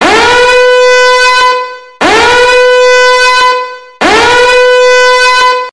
emergency_alarm.mp3